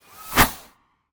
bullet_flyby_06.wav